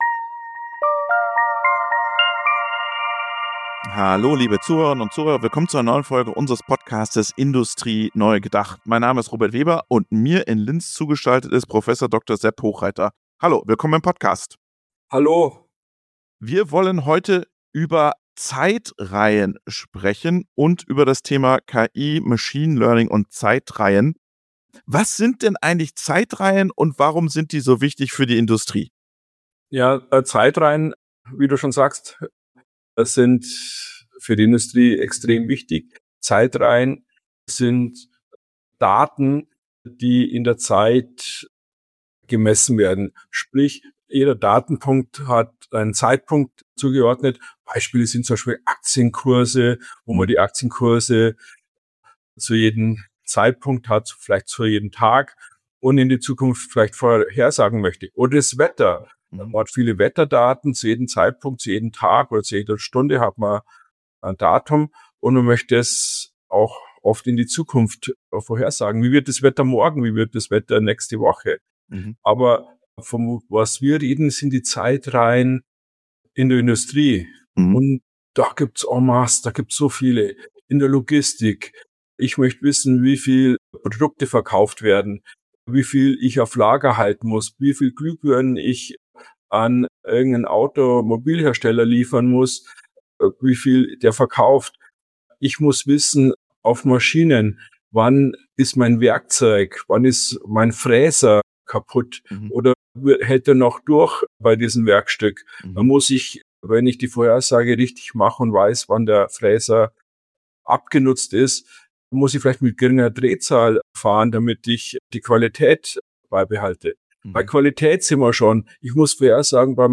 Im Interview erklärt Sepp Hochreiter, wie er das Modell gebaut hat, was State Tracking ist, wie er auf die Geräte gekommen ist und was die nächsten Schritte sind.